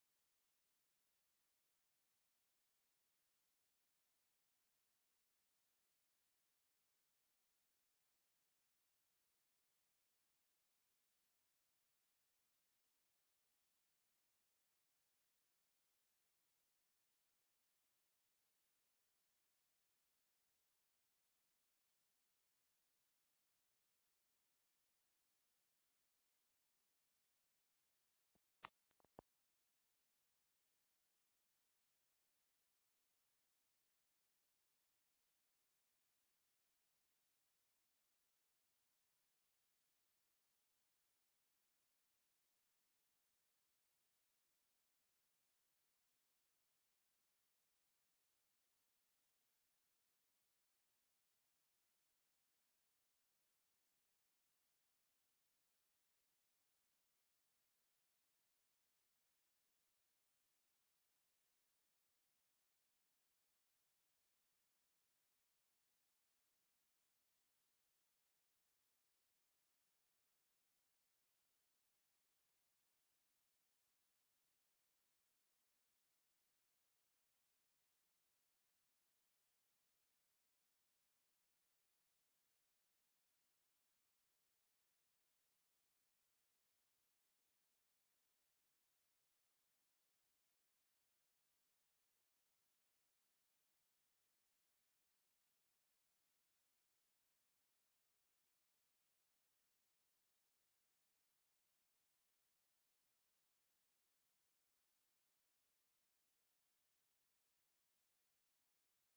MALIBRAN的確是老錄音，而且大多接近百年前的錄音，
但大部分的音質都不差，在情感、音色表現上都沒有問題。
再把不同演唱者的版本放入，這些演唱者都是當時的一時之選。